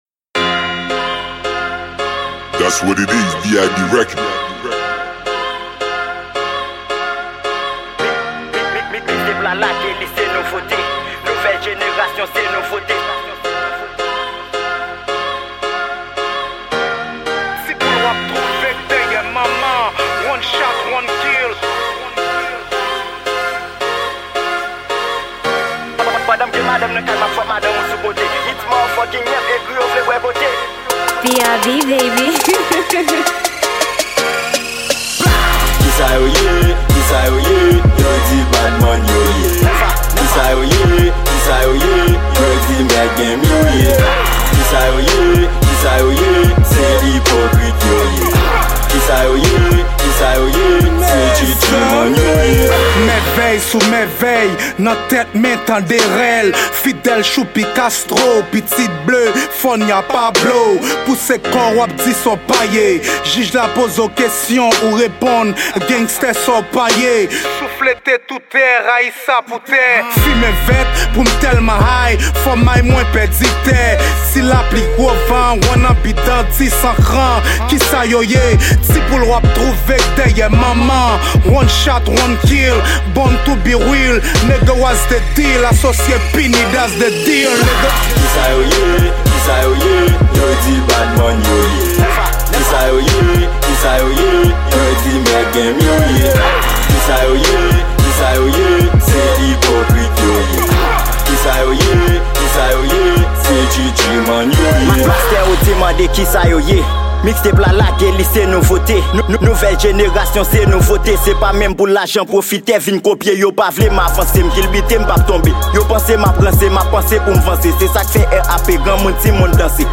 Genre: RRAP.